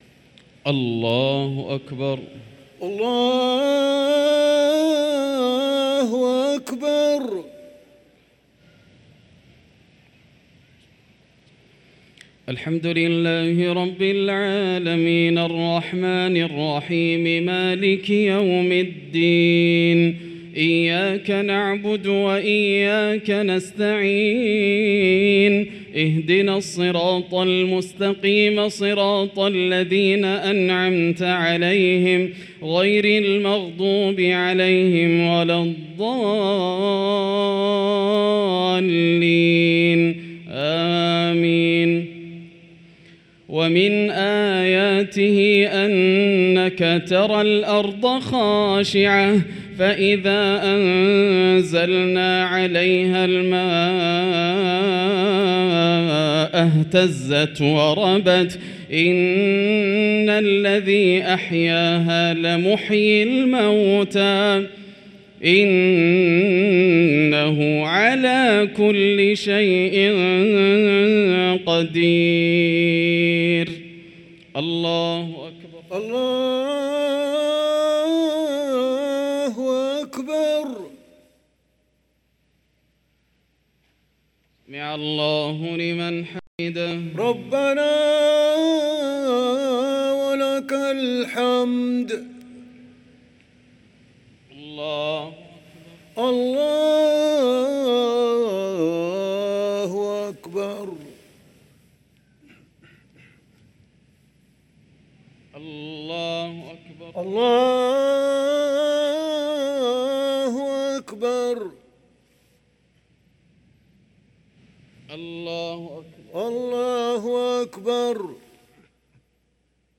صلاة المغرب للقارئ ياسر الدوسري 6 صفر 1445 هـ
تِلَاوَات الْحَرَمَيْن .